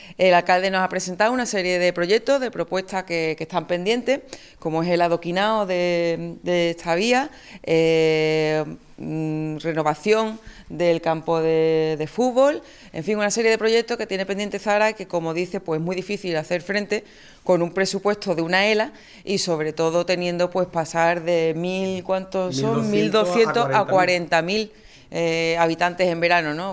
“Nos va a tener aquí para apoyarle”, ha afirmado en su atención a los medios de comunicación para ratificar el compromiso con las ELA.
Corte de Almudena Martínez